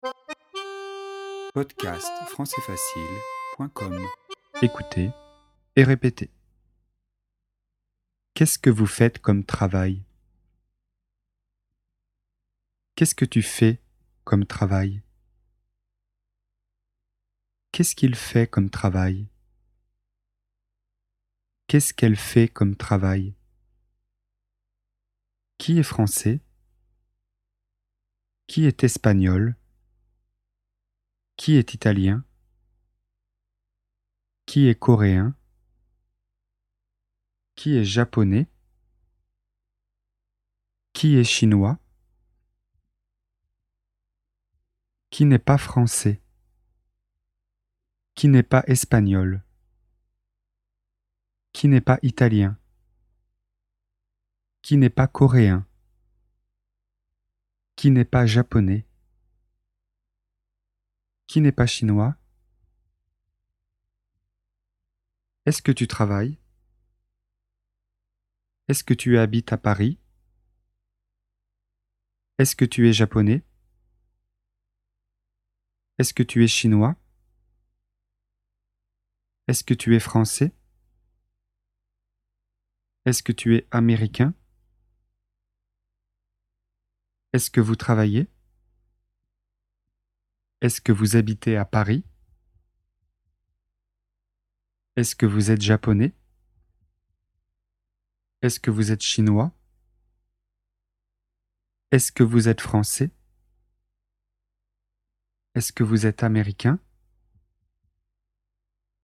Cours sur les premiers pas en français, niveau débutant (A1).
Ecoutez et répétez